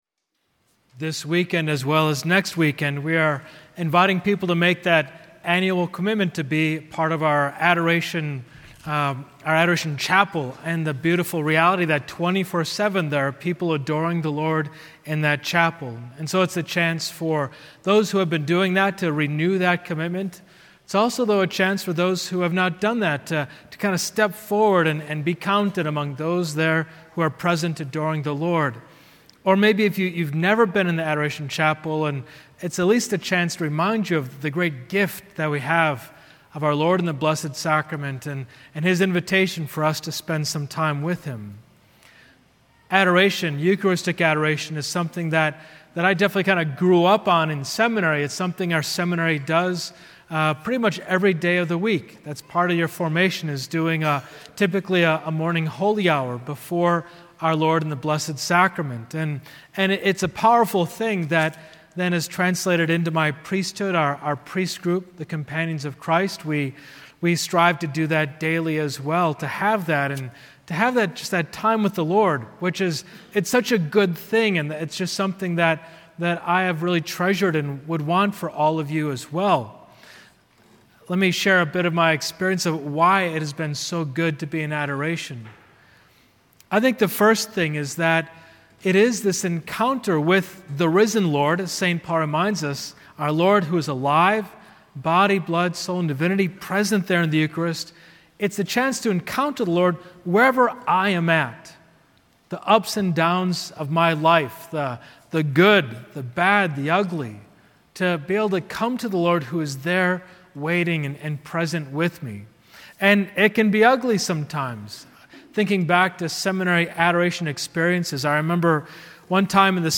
6th SOT Homily